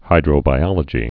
(hīdrō-bī-ŏlə-jē)